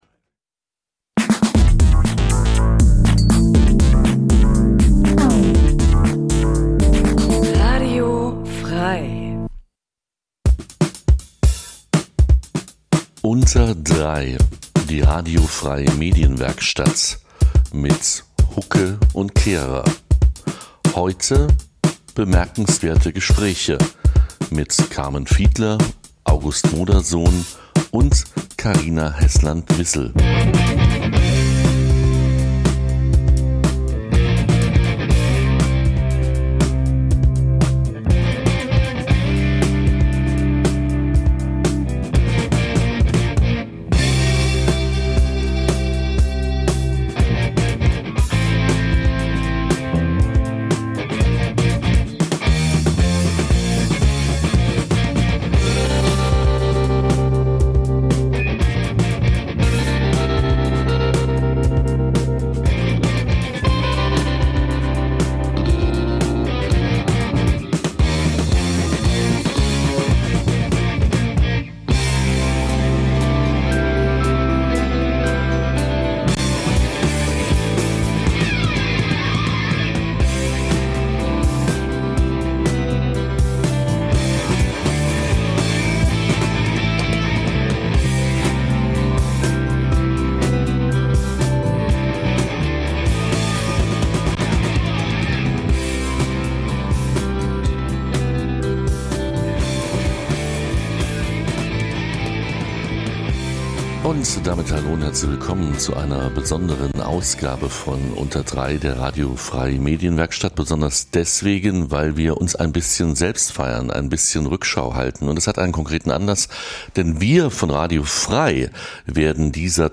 In �Unter Drei� widmen wir uns einmal im Monat dem Th�ringer Journalismus. In die einst�ndigen, thematischen Sendungen laden wir stets einen Gast ein, der oder die aus seinem/ihrem journalistischen Alltag im Freistaat erz�hlt. Dabei wollen wir nicht nur die Unterschiede der drei Gewerke Radio, Fernsehen, Zeitung/Online beleuchten, sondern auch einen Blick auf verschiedene Sender und Verlage werfen.